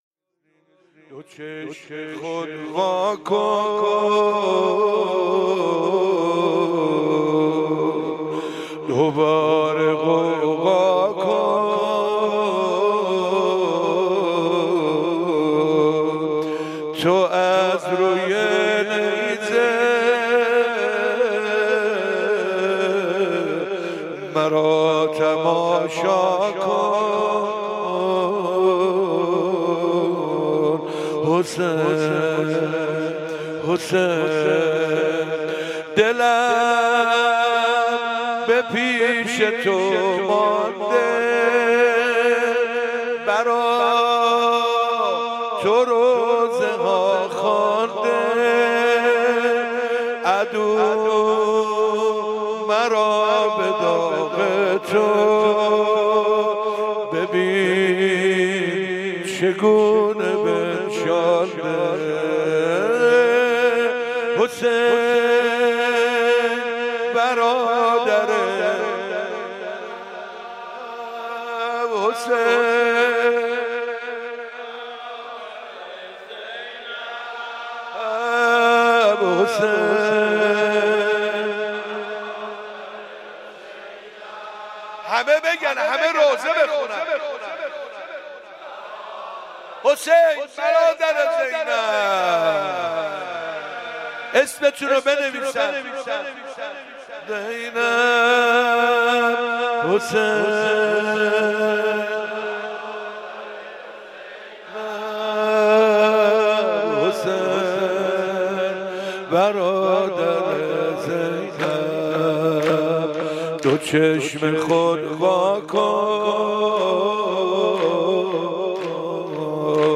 21 محرم 97 - آستان مقدس امامزاده عبدالله - نوحه - دو چشم خود وا کن
محرم 97